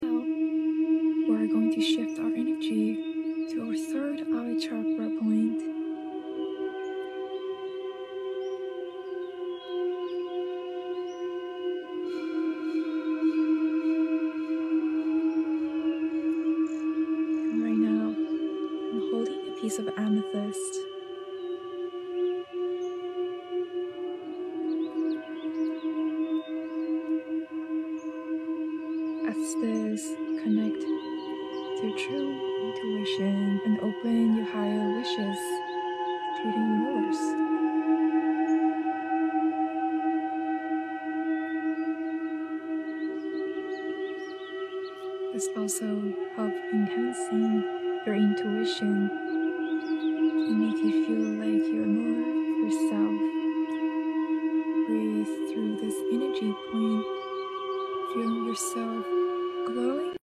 ✨ Energy Sound Healing sound effects free download
✨ Energy Sound Healing Reiki ASMR
🌀 A singing bowl or tuning fork (Tibetan or crystal)